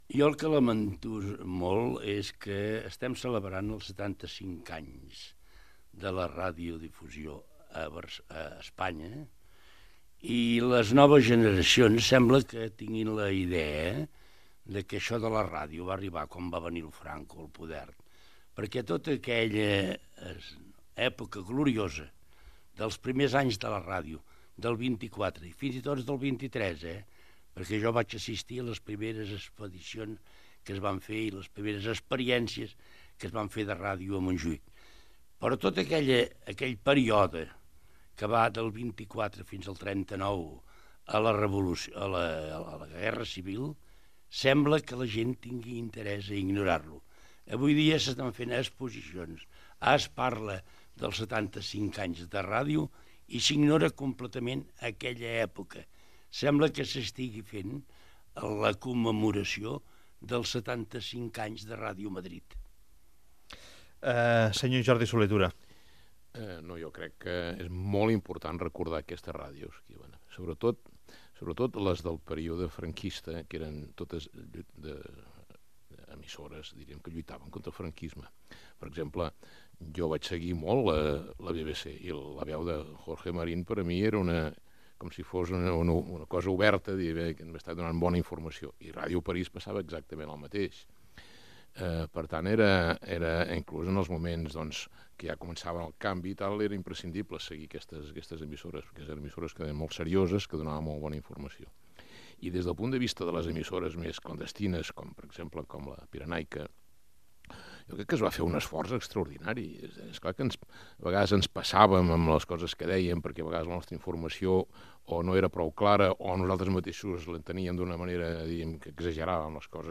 Fragment d'una taula rodona sobre els 75 anys de la ràdio amb Jordi Solé Tura
Info-entreteniment